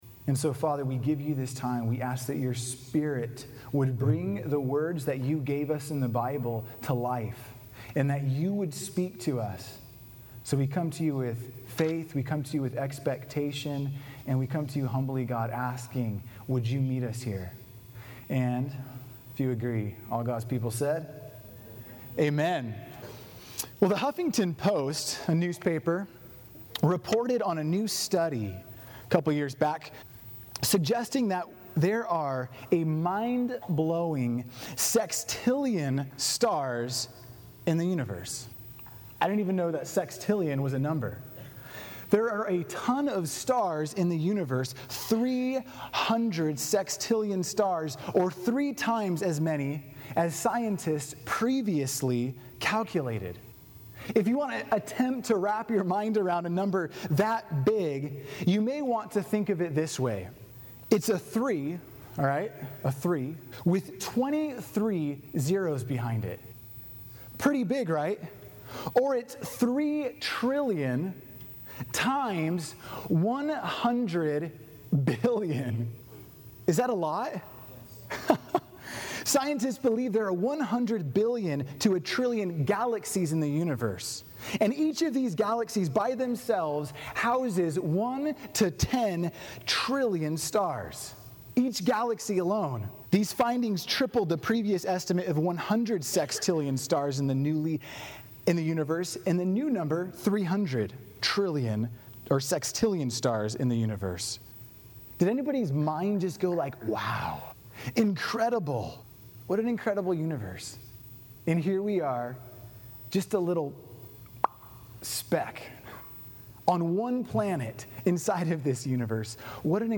Click Below to listen! Transcript/Sermon Notes: Oftentimes God can blow our minds with how big and vast and great and WONDERful He is.